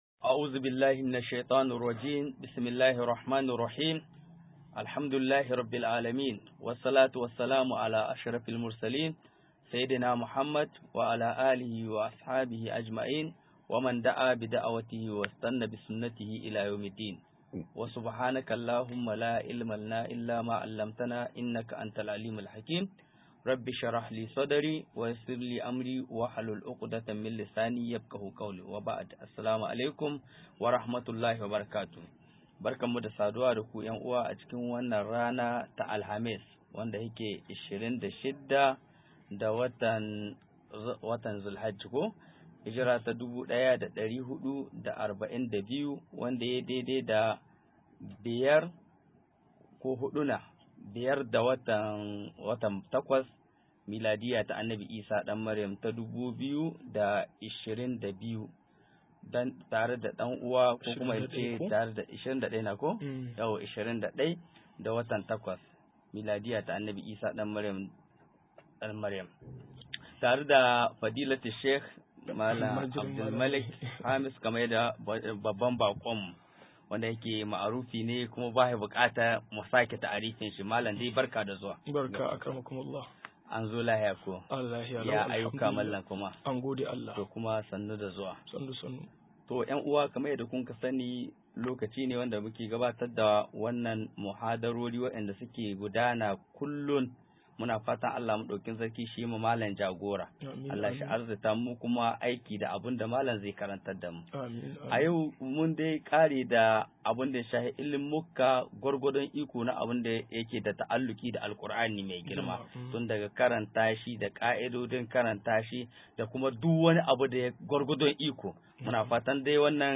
174-Ka i d'odin Ilimin Hadisi - MUHADARA